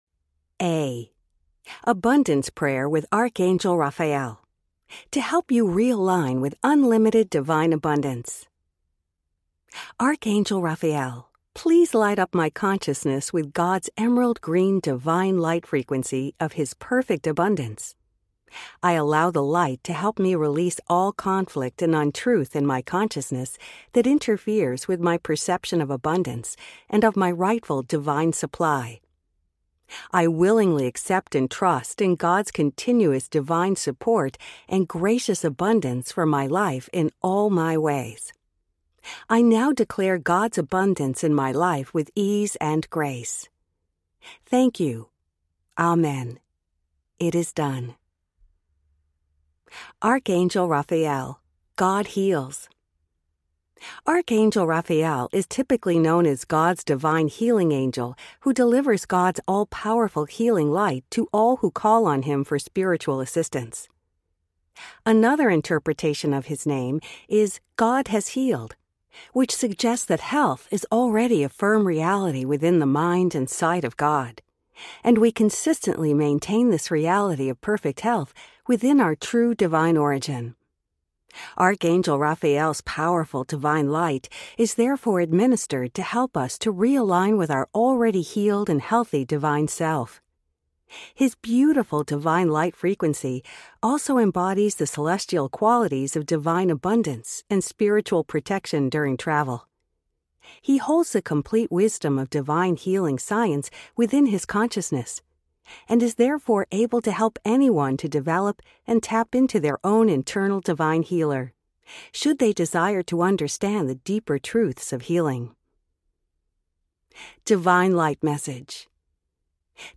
Angel Prayers Audiobook
5.0 Hrs. – Unabridged